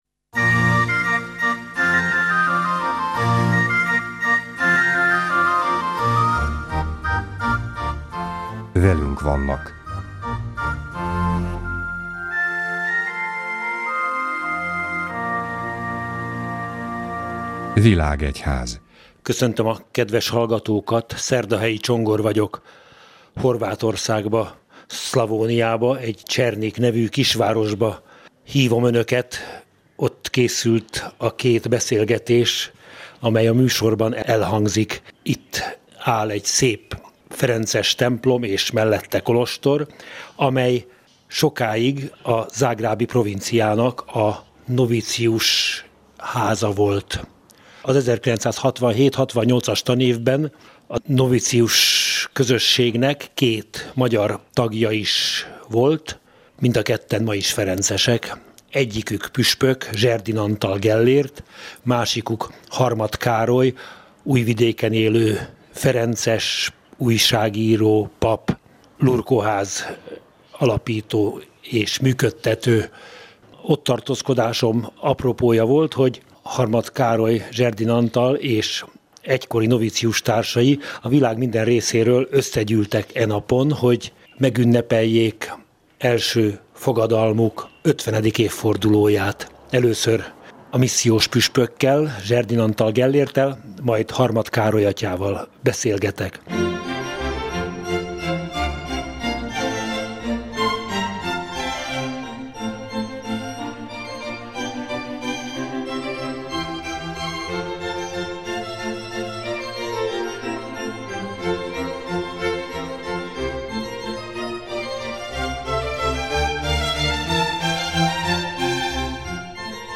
A két délvidéki magyar ferencessel a helyszínen készített interjút a Katolikus Rádió.